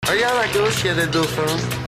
dauphins.mp3